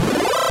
level_up.mp3